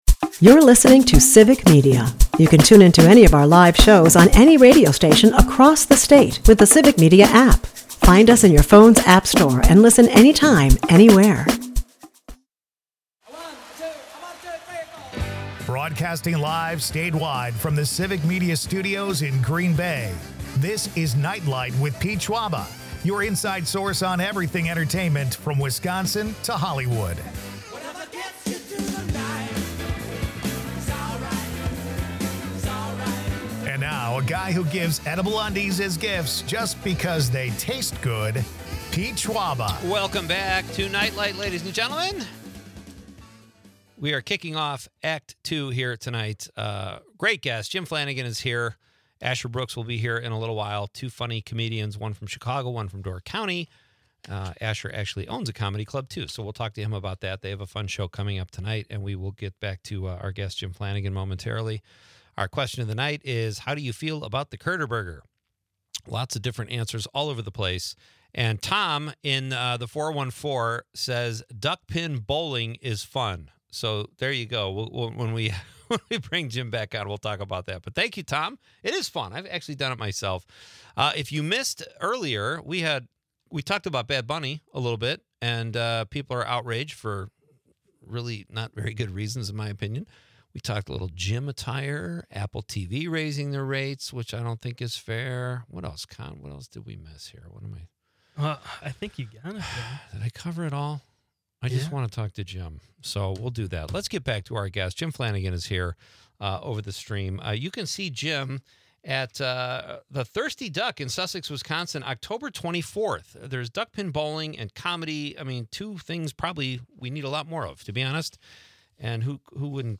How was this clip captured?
They dive into food nostalgia with discussions on Waffle House and the infamous Culver's Curder Burger, sparking debate among listeners. The episode is a delightful mix of laughter, local culture, and the unique challenges and joys of life in Wisconsin's comedy scene.